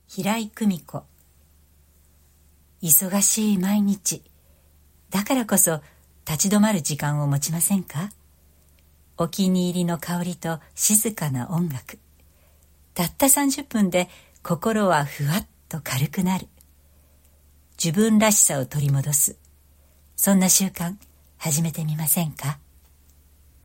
ボイス